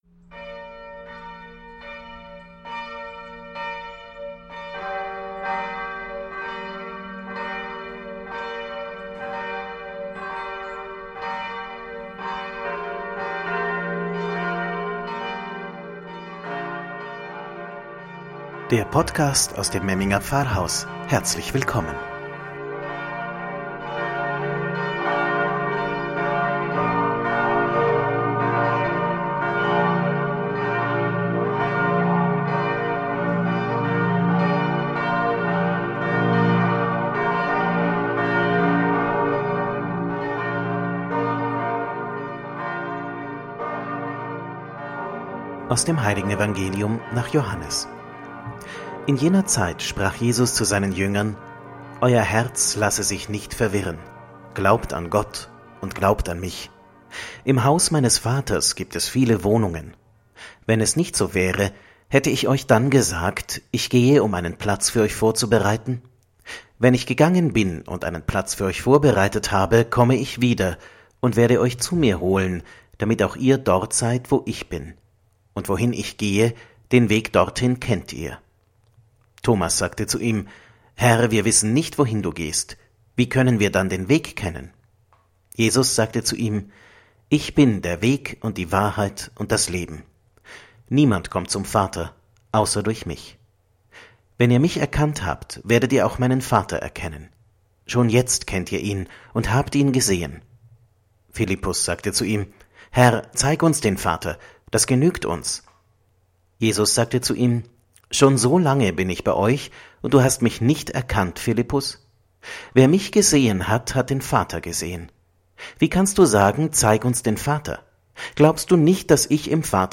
„Wort zum Sonntag“ aus dem Memminger Pfarrhaus – Jesus ist die Entscheidungskategorie